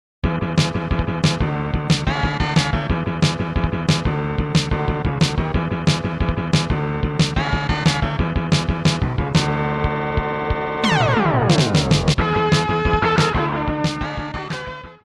※一部の楽曲に収録の都合によりノイズが入る箇所があります。